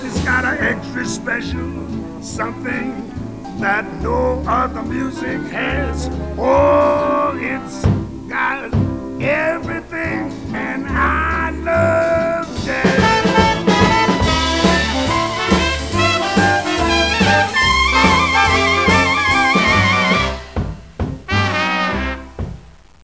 jazz.au